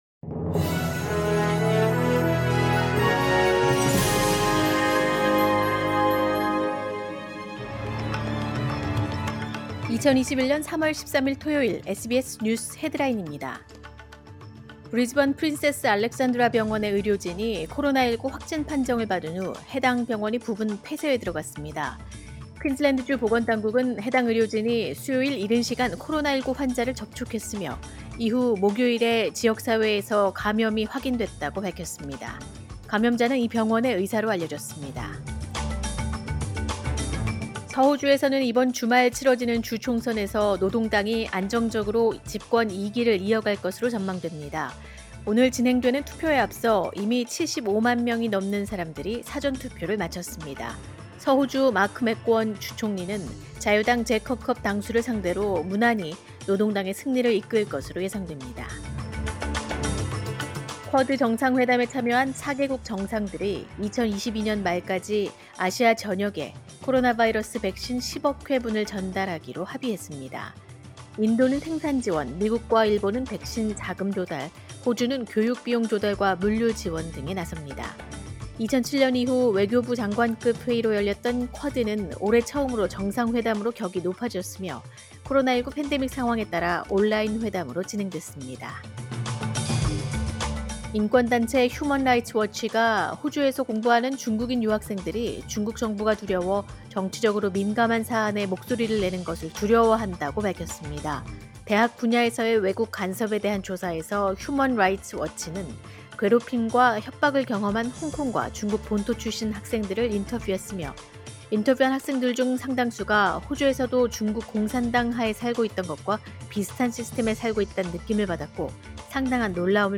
2021년 3월 13일 토요일 오전의 SBS 뉴스 헤드라인입니다.